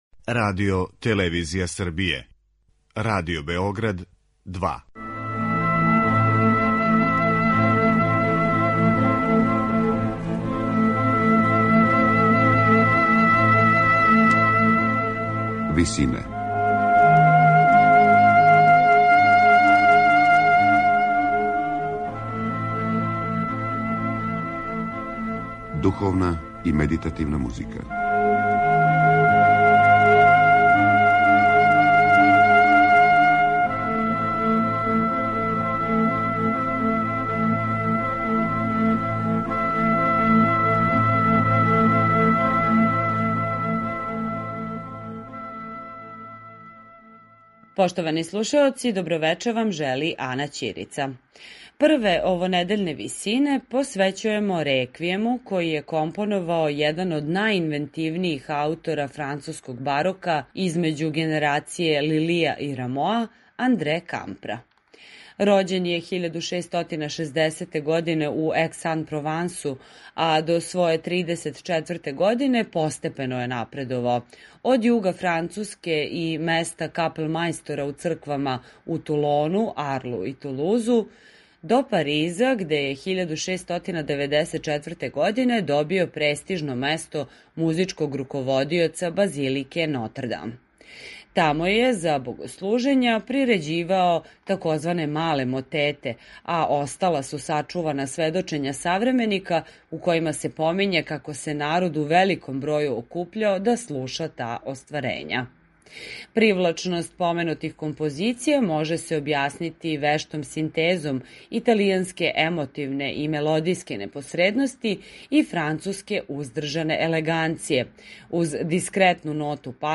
Реквијем